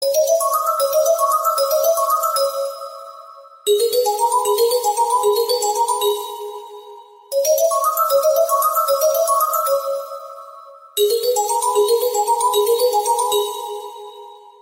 Рингтон Простая и красивая мелодия на звонок